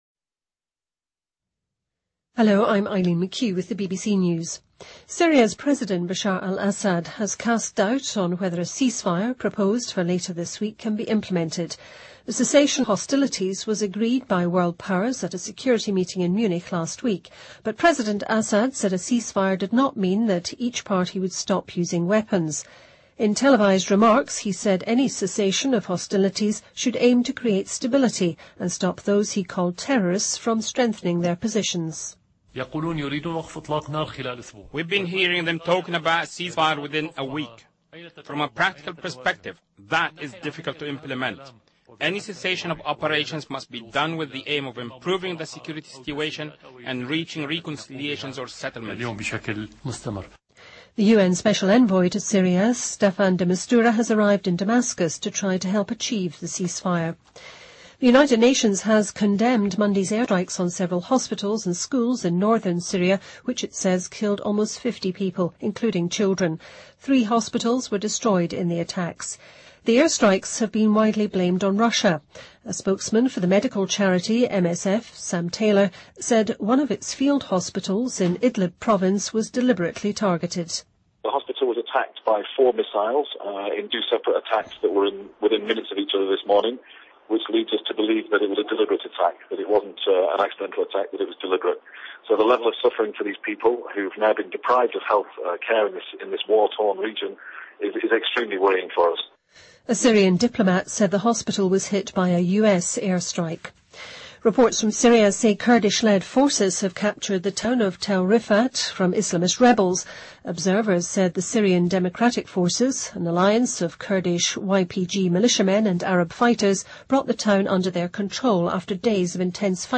BBC news,欧洲理事会主席警告英国退出欧盟后果
日期:2016-02-17来源:BBC新闻听力 编辑:给力英语BBC频道